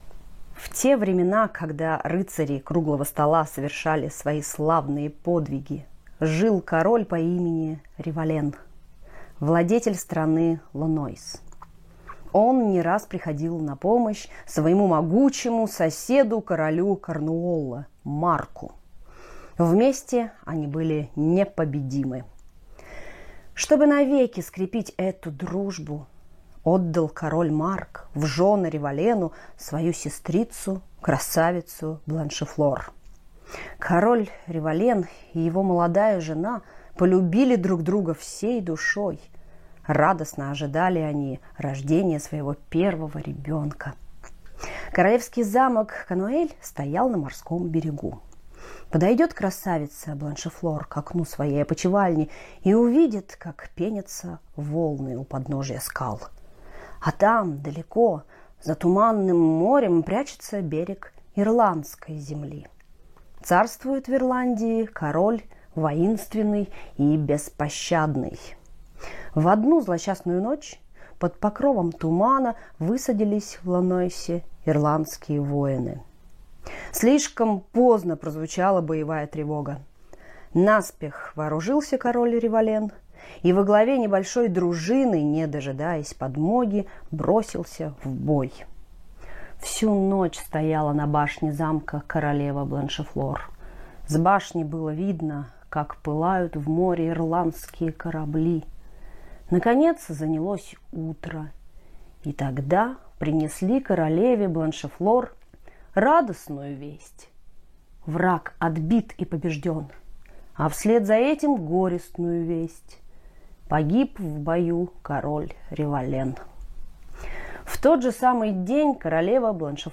Тристан и Изольда - аудио легенда - слушать онлайн